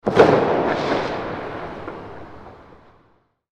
Firework Blast Echo Sound Effect
Description: Firework blast echo sound effect. Loud firework blast on the street with natural echo.
Firework-blast-echo-sound-effect.mp3